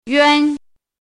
yuān
[ yuān ]
yuan1.mp3